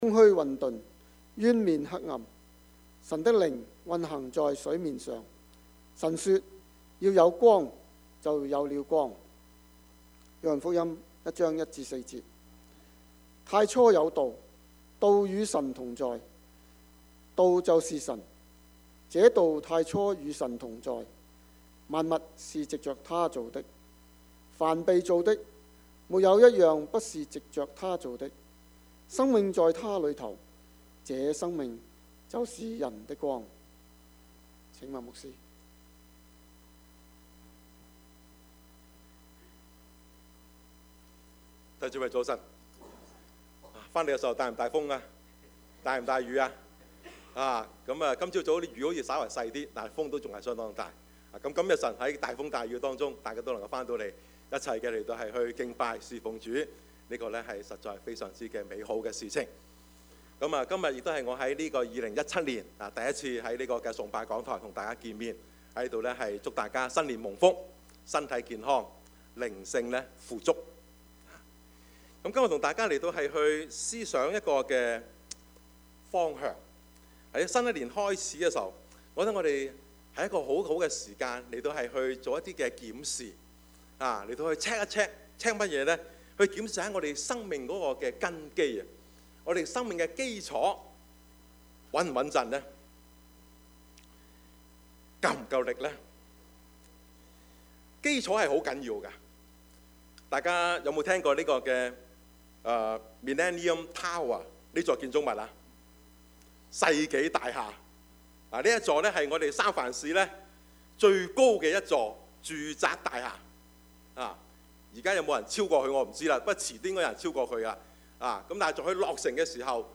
Service Type: 主日崇拜
Topics: 主日證道 « 回顧與回應 箴言中的智慧 »